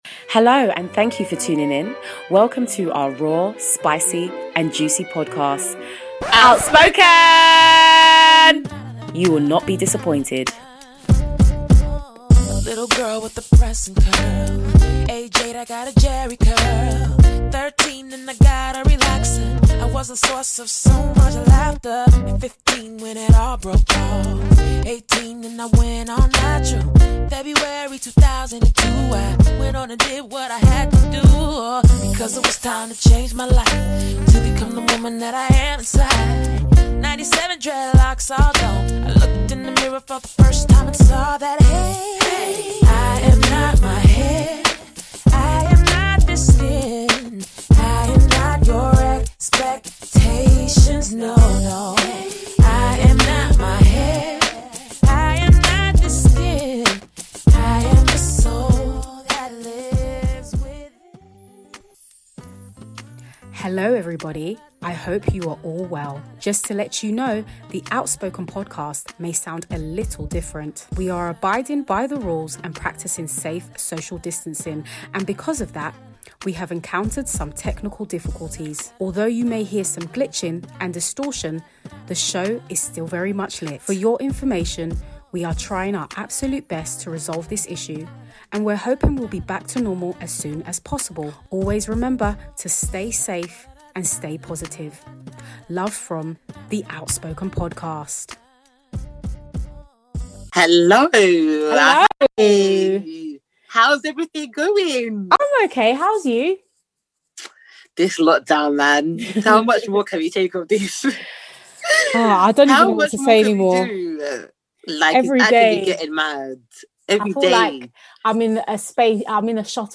You've come to the right place That is if you're looking for sharp, candid and undeniably risque conversation.
We are two London based Christian girls with burning discussions that we need to desperately get off our chest!